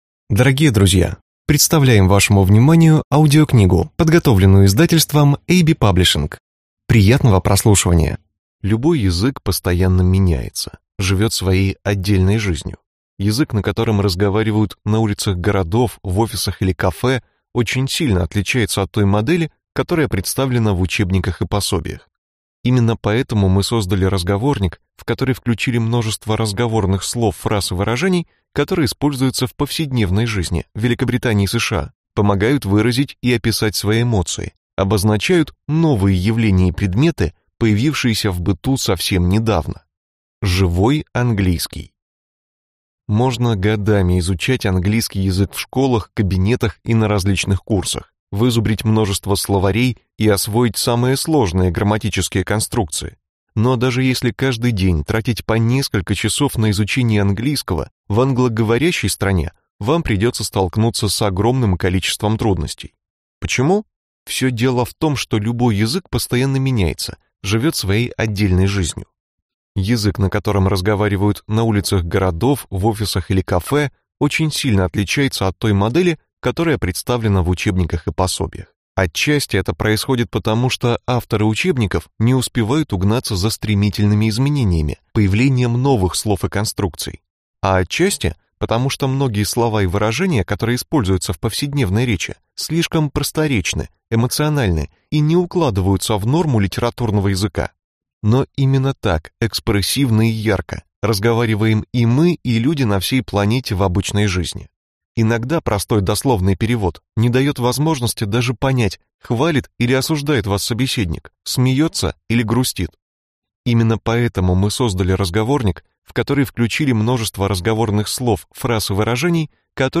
Аудиокнига Живой английский | Библиотека аудиокниг
Прослушать и бесплатно скачать фрагмент аудиокниги